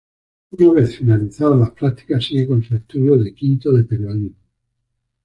/ˈpɾaɡtikas/